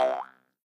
Звуки удивления, мультфильмов
Звук бенг прыжка анимационного персонажа